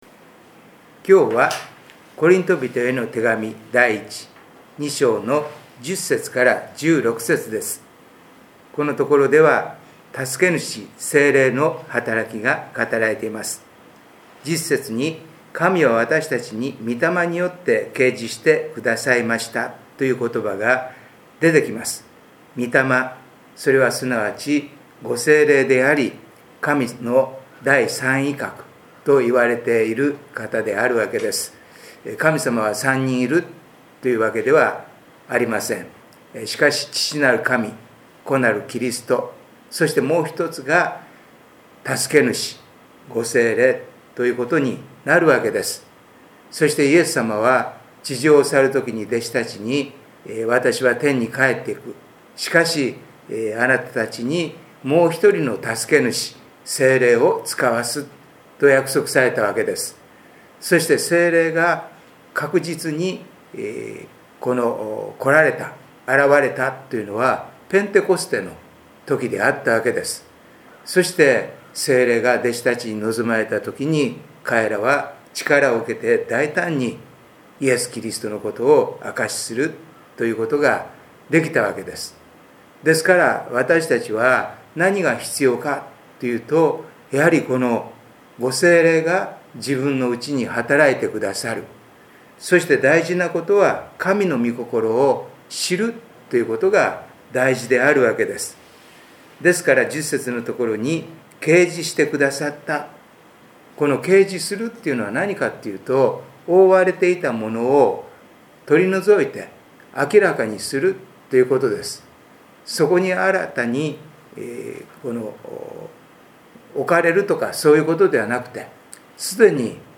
礼拝メッセージ「いのちに至るかおり」│日本イエス・キリスト教団 柏 原 教 会